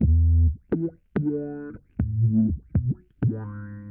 BASS LOOPS - PAGE 1 2 3 4 5